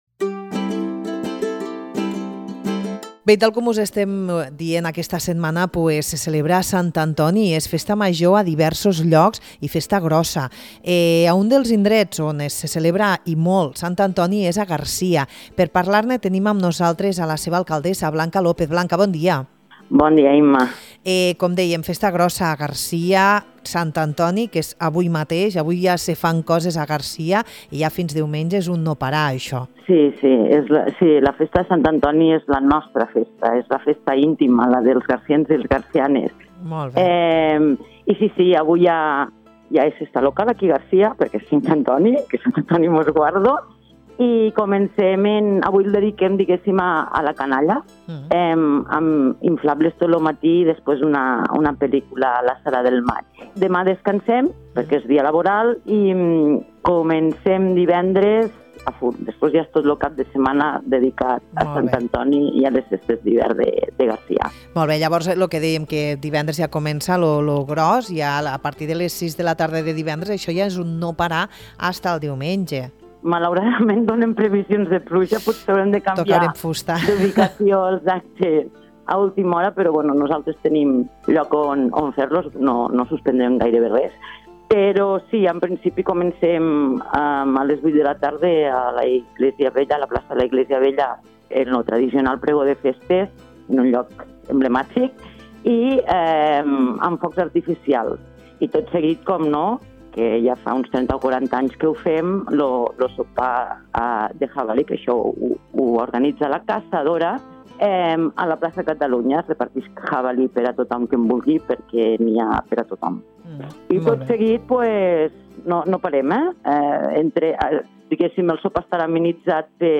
Arriben les Festes de Sant Antoni a Garcia i en parlem amb Blanca López